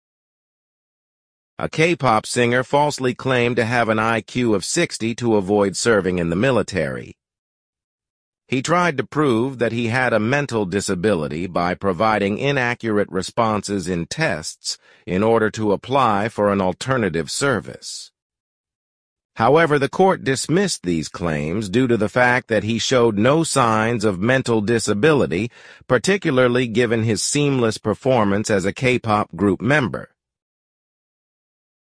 [Korea Herald Summary]
Super Realistic AI Voices For Your Listening Practice